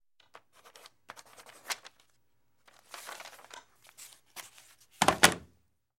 Pencil Writing On Paper Movement; Pencil Writing On Paper With Pad Down At End. - Pencil Writing